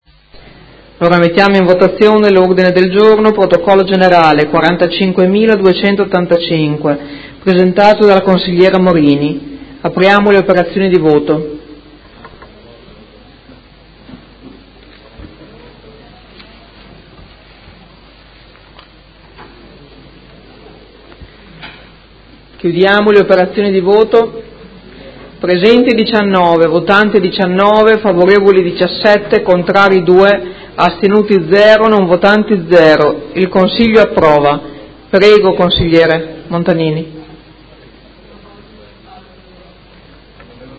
Seduta del 26/04/2018 Mette ai voti.
Presidentessa